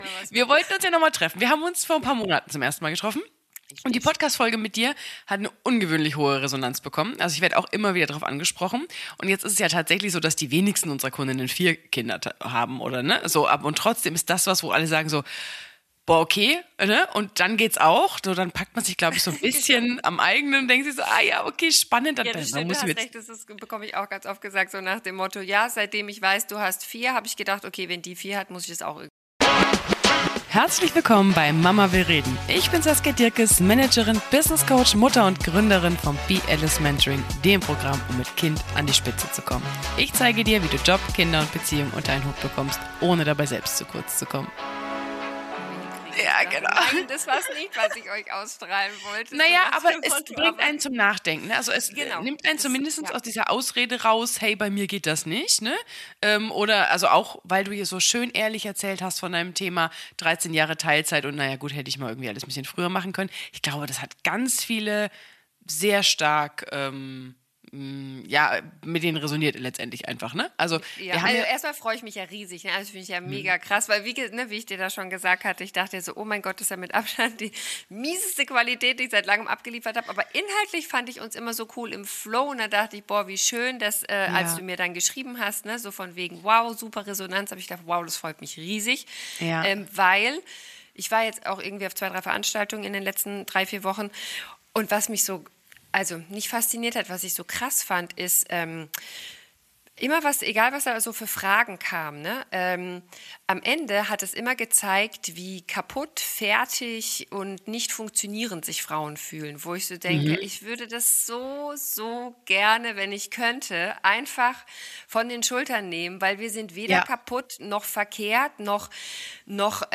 In dieser Folge von Mama will reden spreche ich mit einer vierfachen Mutter, Konzernfrau und Aktivistin für echte Vereinbarkeit über: • Teilzeit und Karriere • Part Time Wage Gap • Care Arbe...